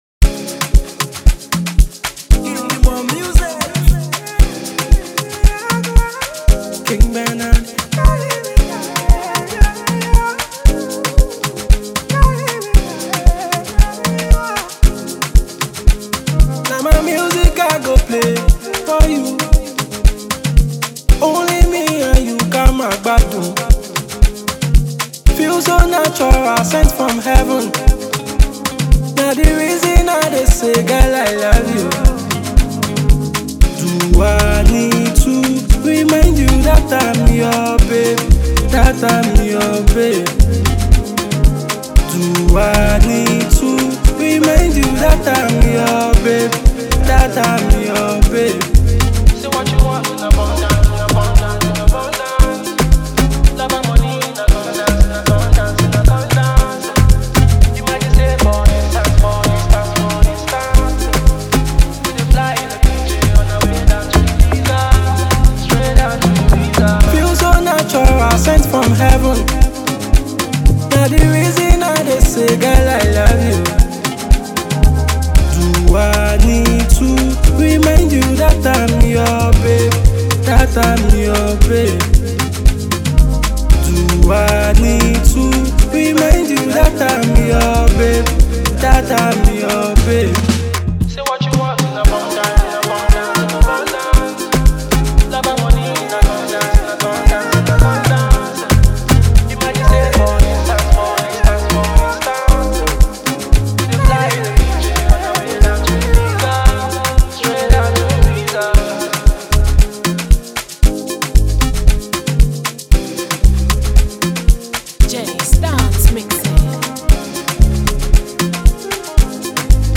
afrobeats
Widely known for his soulful melodies and heartfelt lyrics.